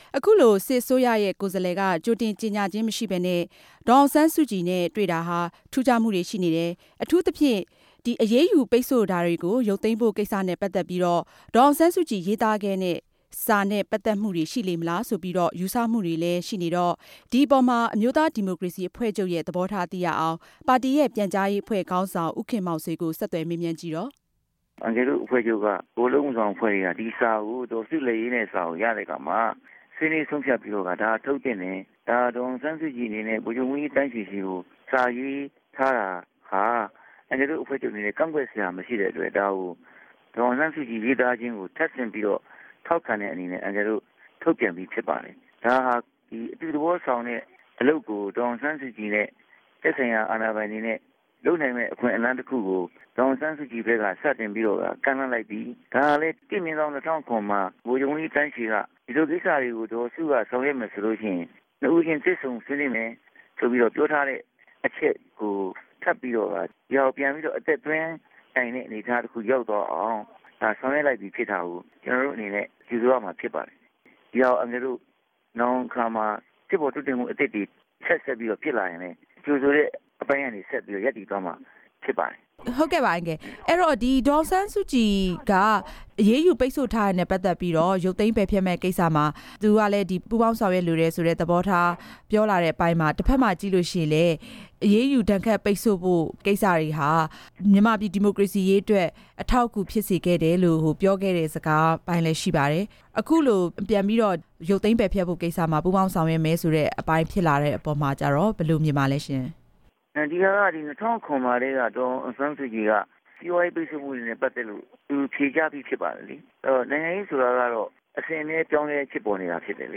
ဆက်သြယ်မေးူမန်းခဵက်။